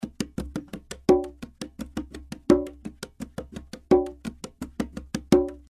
170 BPM conga loops (11 variations)
Real conga loops played by professional percussion player at 170 BPM.
The conga loops were recorded using 3 microphones,
(AKG C-12 VR , 2 x AKG 451B for room and stereo).
All the loops are dry with no reverb ,light EQ and compression, giving you the Opportunity to shape the conga loops in your own style and effect. The conga loops are for salsa beat, will work great If you are composing a latin salsa song.
*- room reverb was added to the conga loops in the preview.